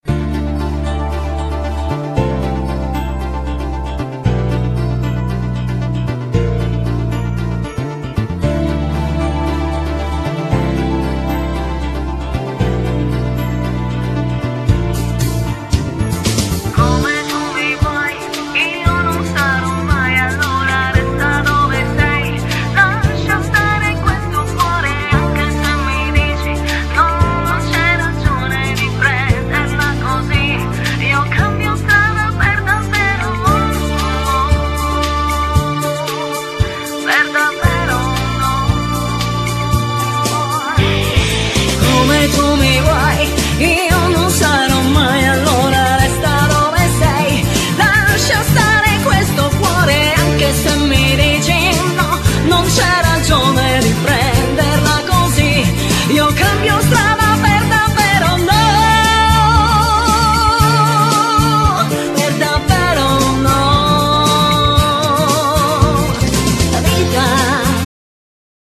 Genere : Pop / Rock